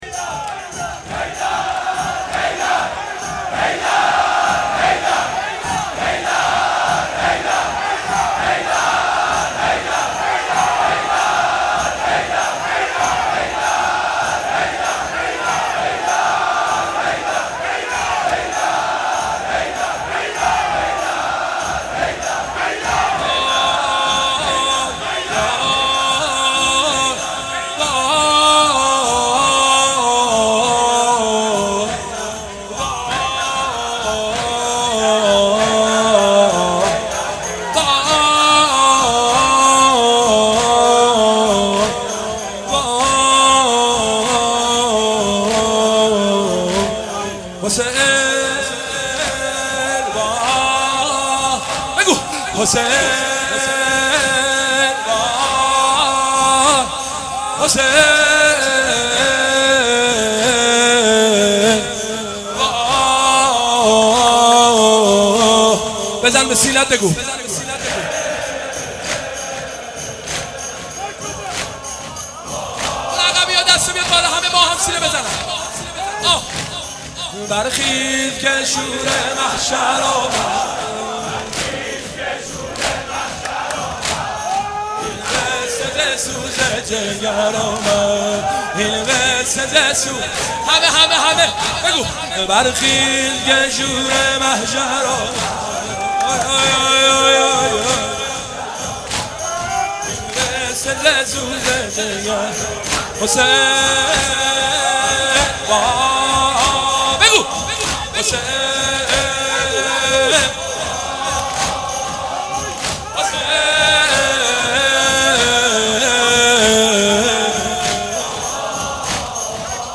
شور و دمام زنی
شور ودمام زنی.mp3